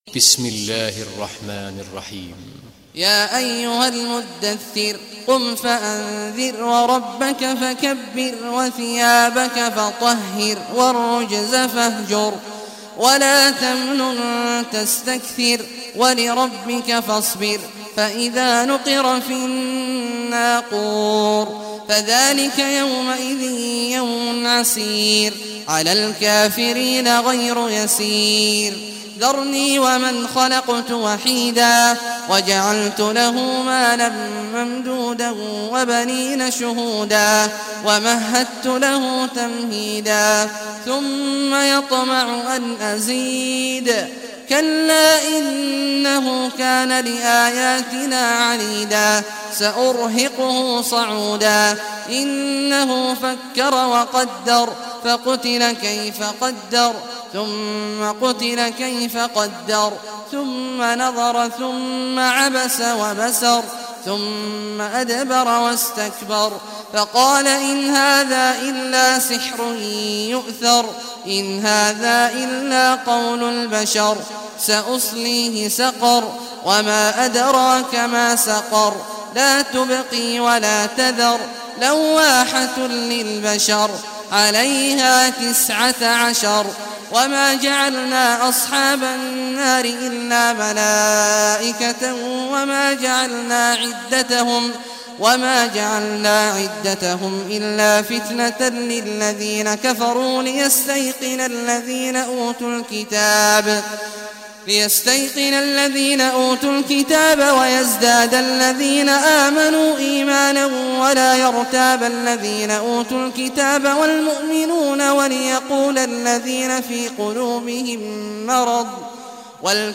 Surah Muddassir Recitation by Sheikh Awad Juhany
Surah e Al-Muddassir, listen or play online mp3 tilawat / recitation in Arabic in the beautiful voice of Sheikh Abdullah Awad al Juhany.